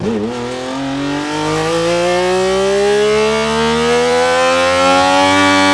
f1_04_accel.wav